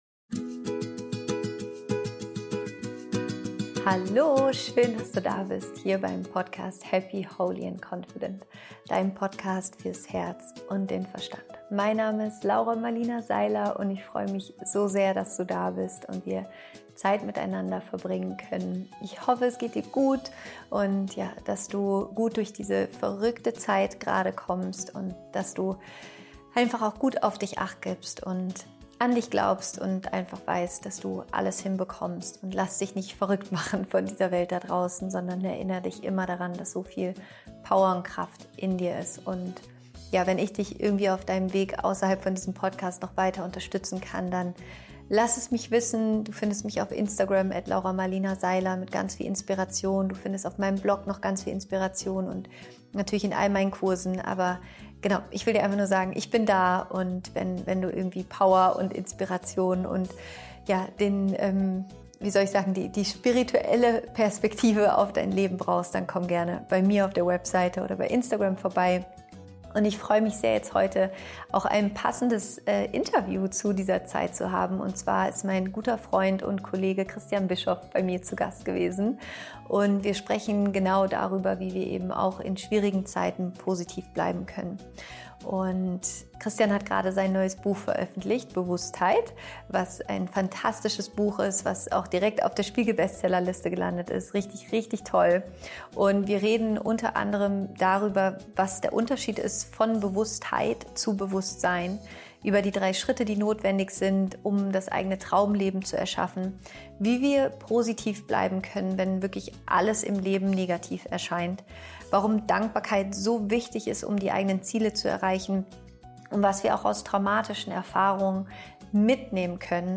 Es ist ein unglaublich positives und empowerndes Interview.